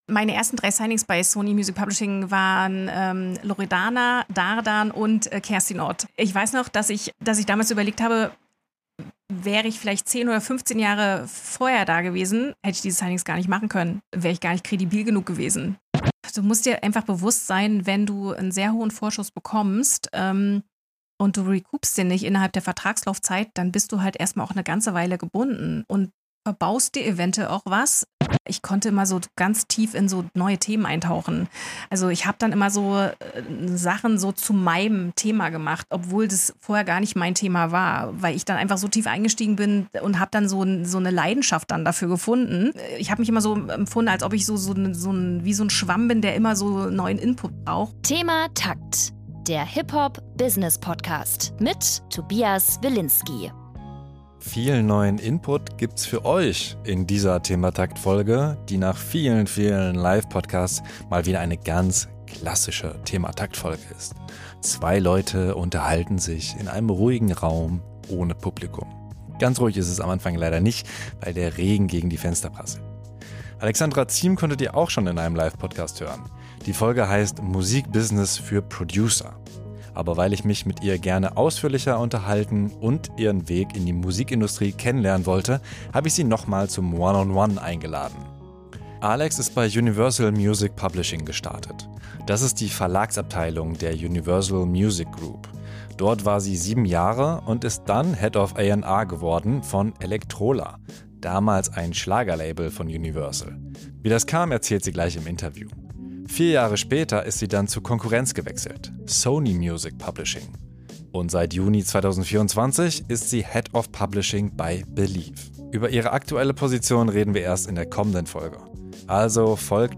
Nach vielen Live-Podcasts mal wieder eine ganz klassische ThemaTakt-Folge ist. Zwei Leute unterhalten sich in einem ruhigen Raum ohne Publikum. Ganz ruhig ist es am Anfang leider nicht, weil der Regen gegen die Fenster prasselt.